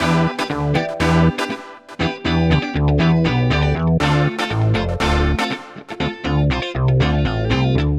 29 Backing PT1.wav